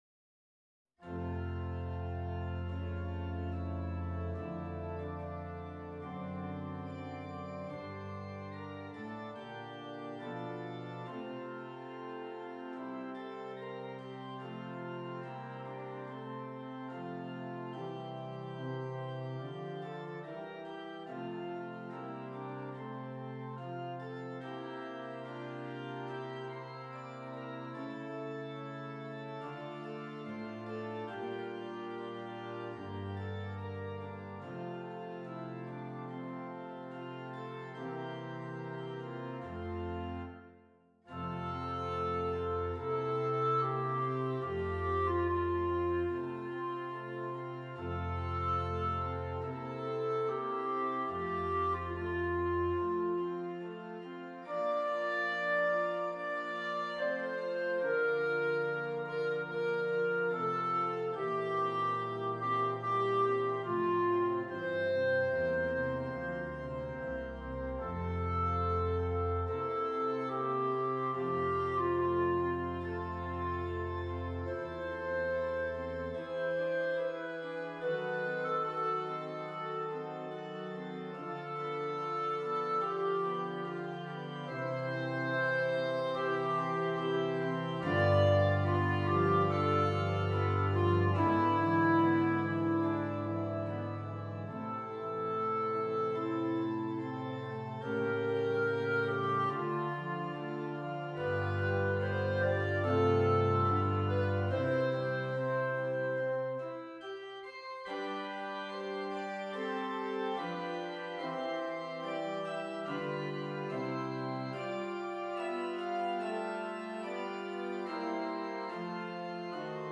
Clarinet and Keyboard